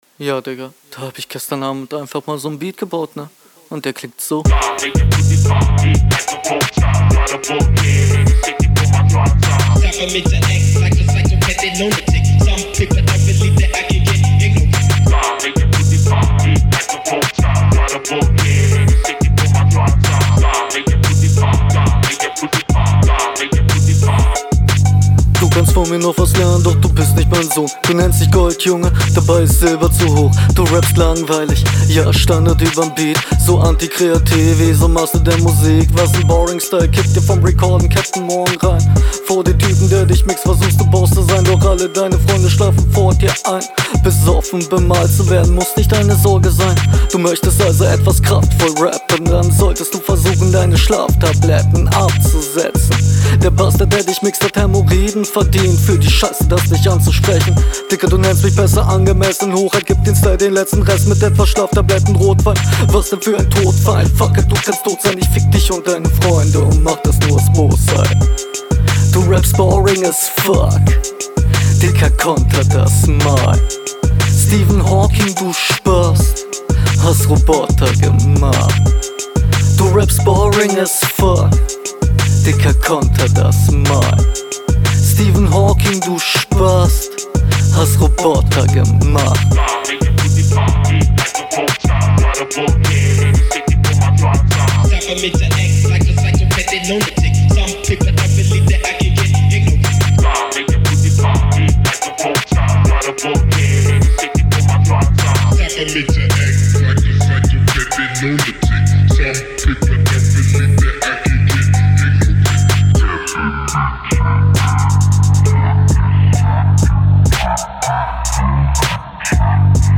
oh was ein tighter beat.
cooler beat mate wieder bisschen austauschbar alles aber kommst cool auf dem beat